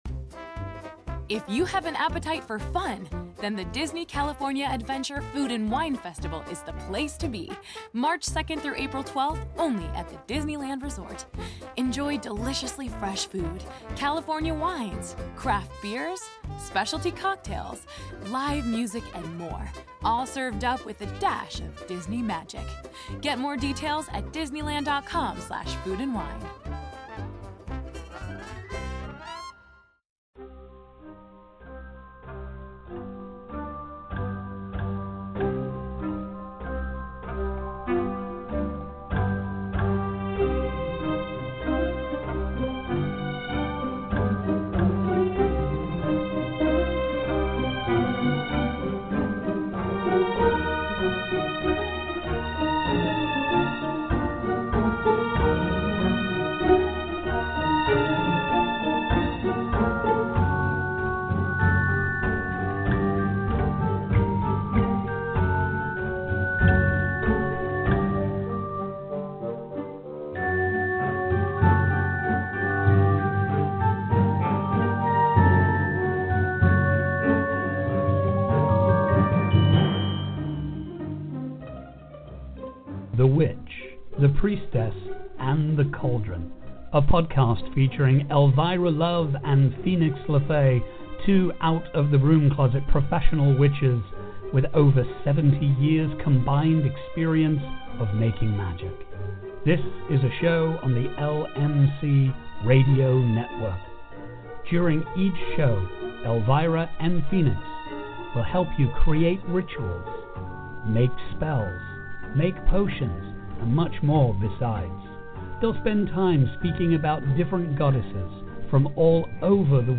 professional witches